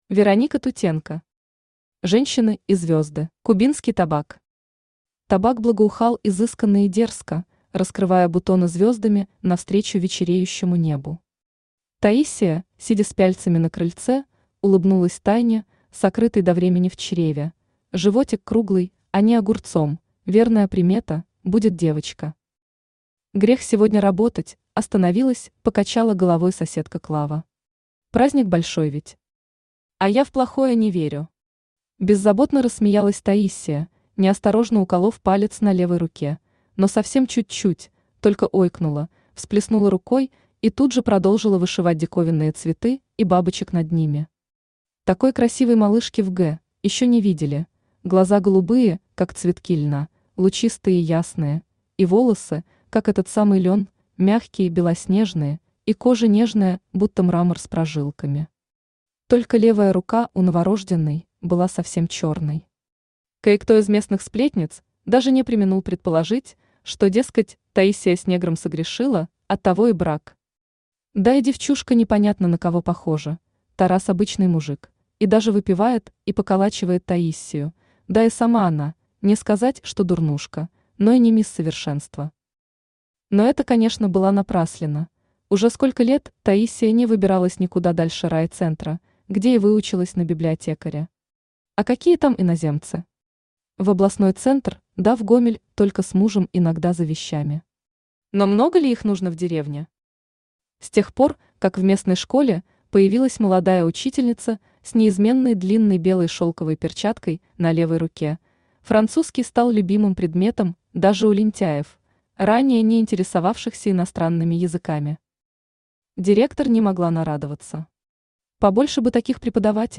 Аудиокнига Женщины и звезды | Библиотека аудиокниг
Aудиокнига Женщины и звезды Автор Вероника Тутенко Читает аудиокнигу Авточтец ЛитРес.